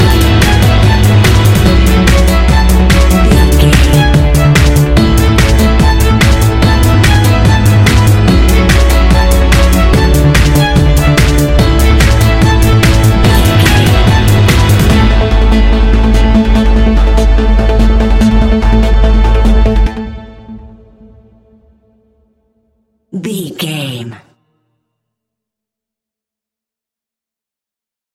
Ionian/Major
energetic
uplifting
hypnotic
drum machine
synthesiser
violin
piano
synth leads
synth bass